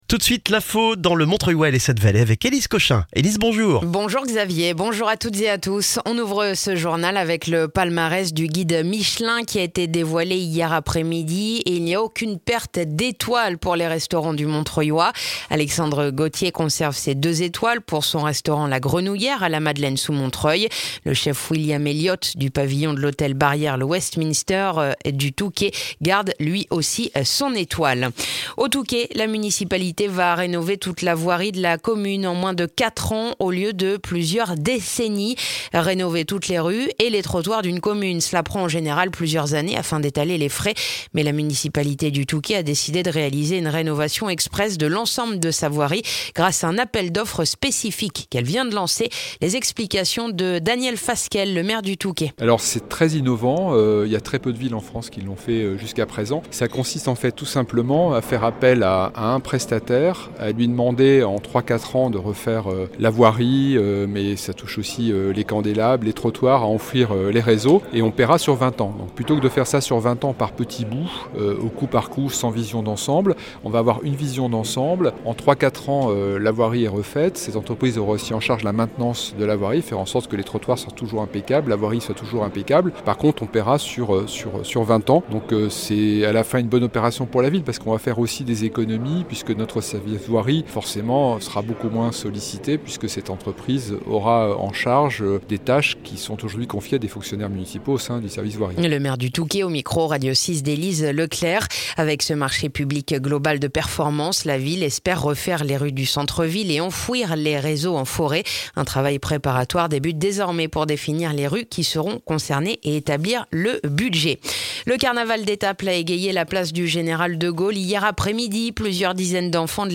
Le journal du mercredi 23 mars dans le montreuillois